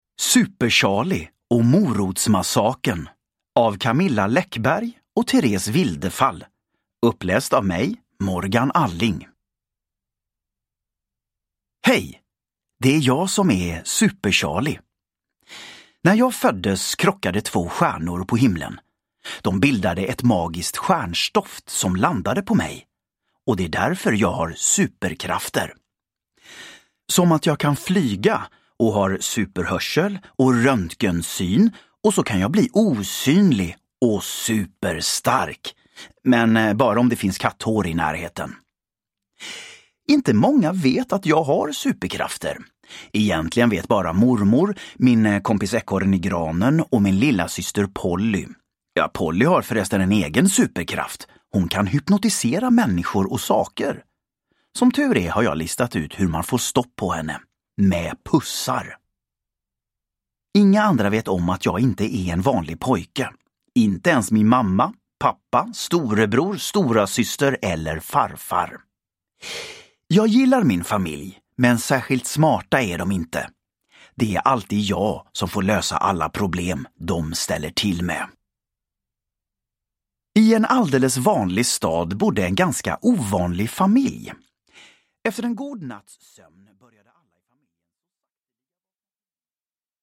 Super-Charlie och morotsmassakern – Ljudbok – Laddas ner
Uppläsare: Morgan Alling